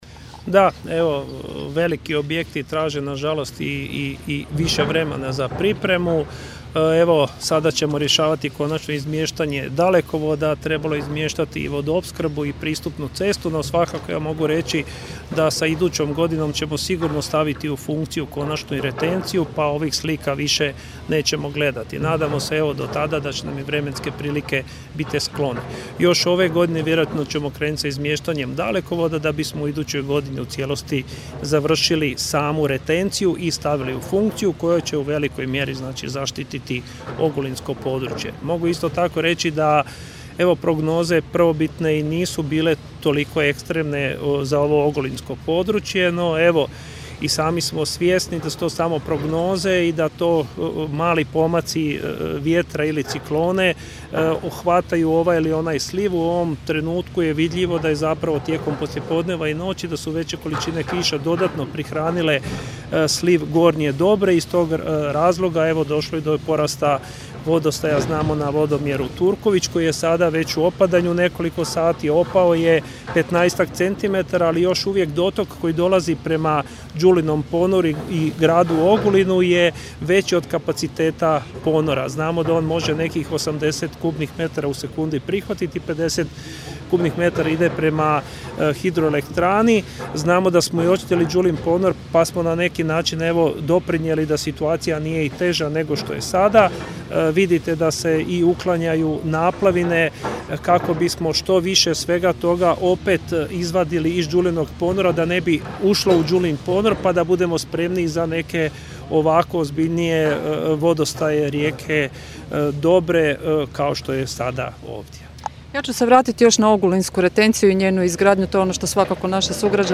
Evo što su rekli okupljenim novinarima:
Glavni direktor Hrvatskih voda Zoran Đuroković odgovara zašto kasni retencija,